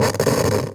radio_tv_electronic_static_12.wav